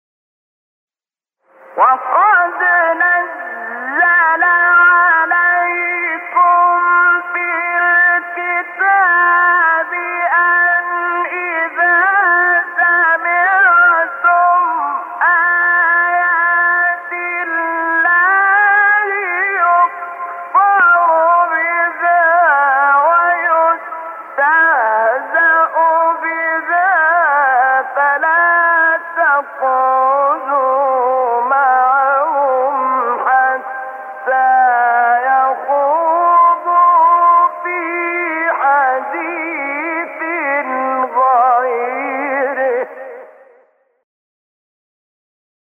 سایت قرآن کلام نورانی- حجاز انورشحات (2).mp3
سایت-قرآن-کلام-نورانی-حجاز-انورشحات-2.mp3